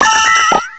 Cri de Ceriflor dans Pokémon Diamant et Perle.